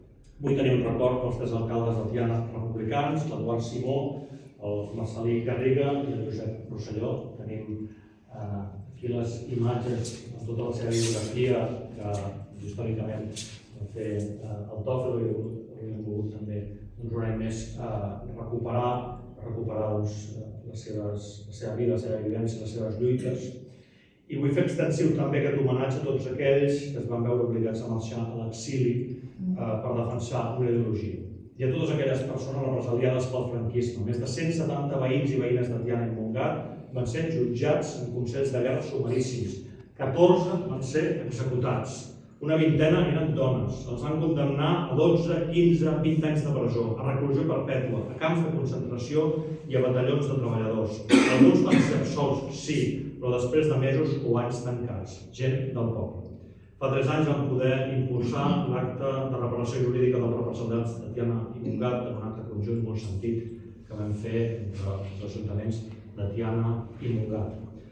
La Sala de Plens ha acollit aquest dimarts 14 d’abril lacte d’homenatge als tres alcaldes republicans que van haver de marxar a l’exili, Eduard Simó, Marcel·lí Garriga i Josep Rosselló. Un homenatge que l’alcalde de Tiana, Isaac Salvatierra, ha volgut fer extensiu també “a tots aquells que es van veure obligats a marxar a l’exili per defensar una ideologia, i a totes aquelles persones represaliades pel franquisme”: